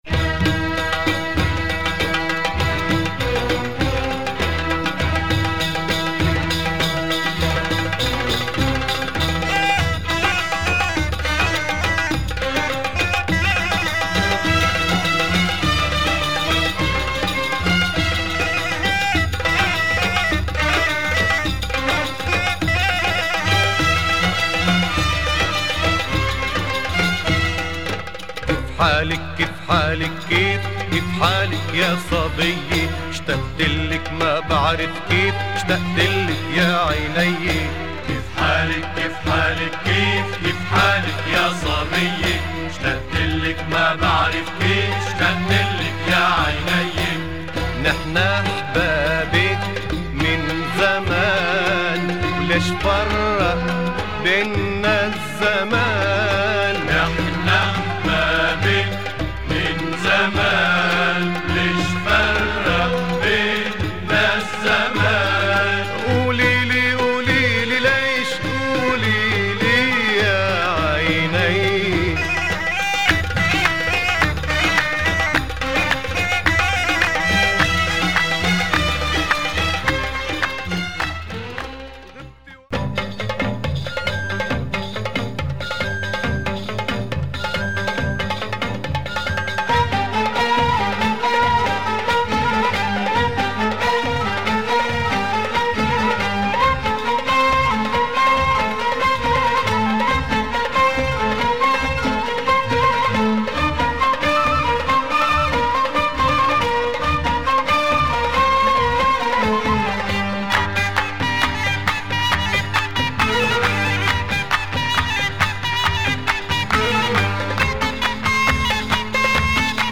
Some pretty good oriental beats here !